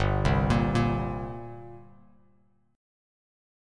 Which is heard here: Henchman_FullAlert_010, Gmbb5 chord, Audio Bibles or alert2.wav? Gmbb5 chord